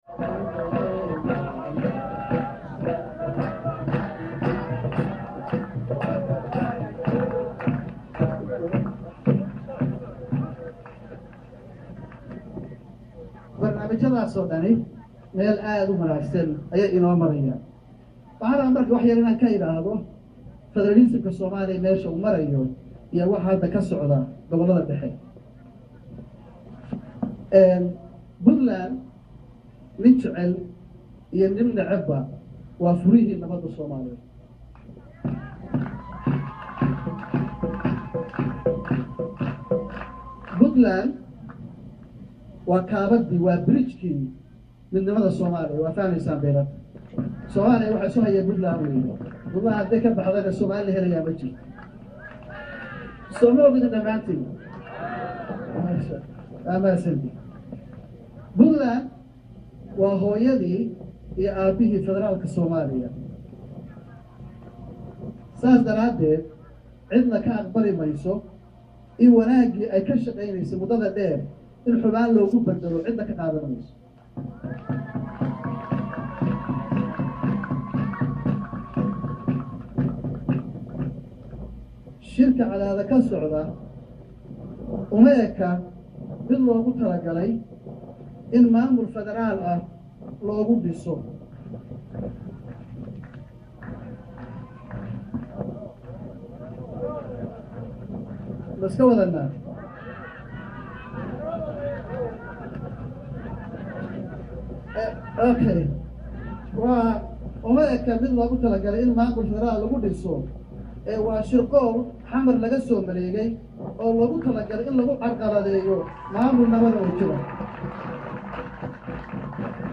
Garoowe–Madaxweynaha Puntland ayaa xafladii kowda Luulyo kaga hadlay arimo badan oo ku saabsan halgankii la soo maray sheegayna in Punltand gooni isku taageyso haddii federaalka la diido laguna so durko dhulka ay leedahay..Wuxuu fariin kulul u direy Xasan Sheekh iyio Jawaari.